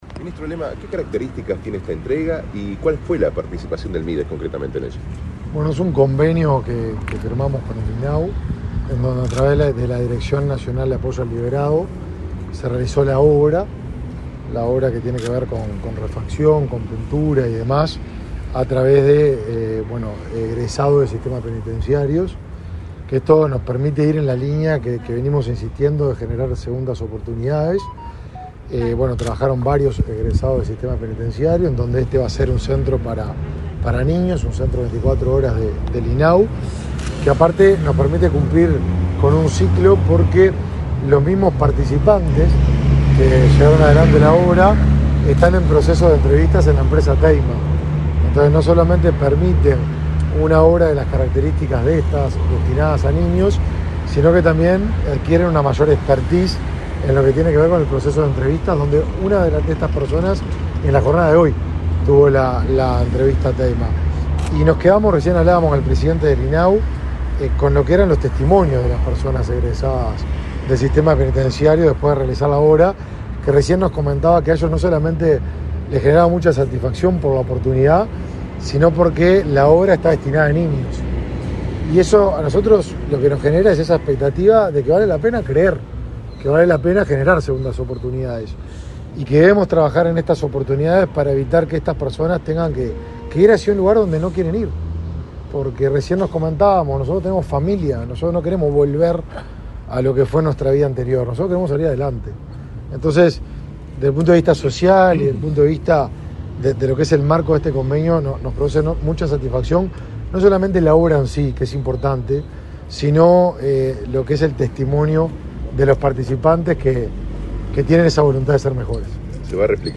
Declaraciones a la prensa del ministro de Desarrollo Social, Martín Lema
El ministro Martín Lema, en declaraciones a la prensa, explicó las características de la iniciativa.